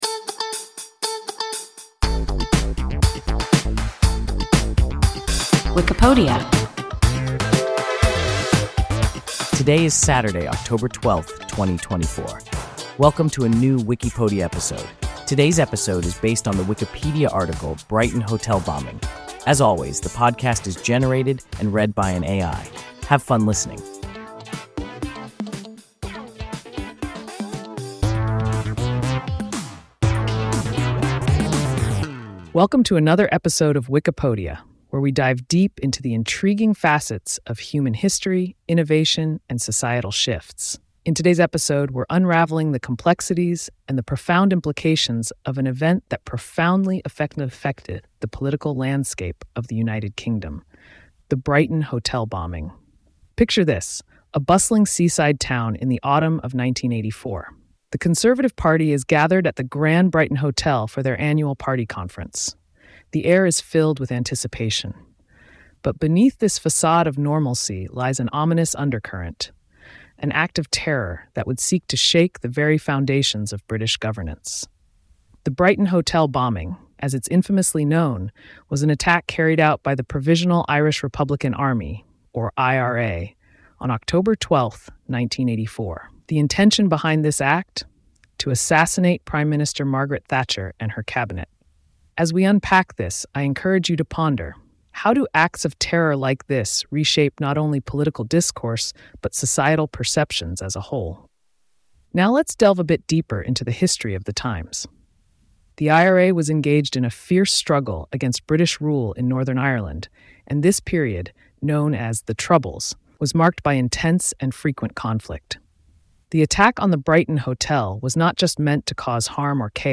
Brighton hotel bombing – WIKIPODIA – ein KI Podcast